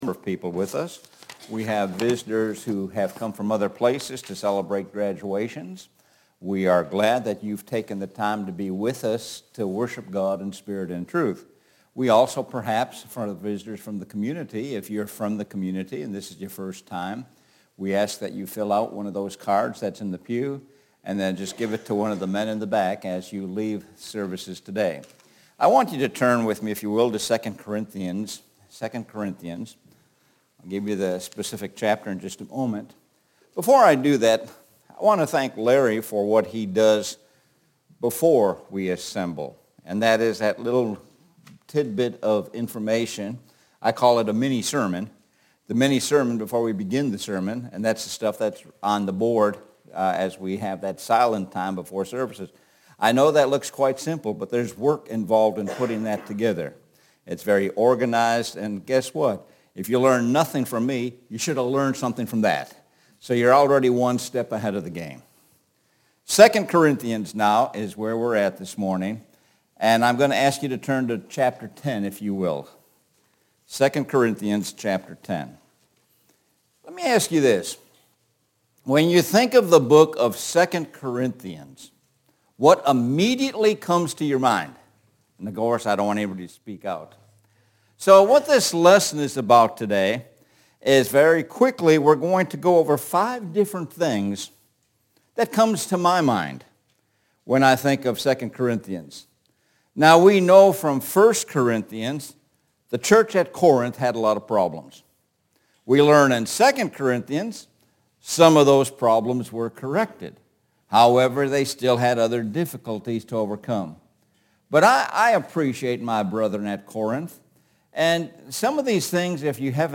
Sun AM Worship – Five Lessons from 2 Corinthians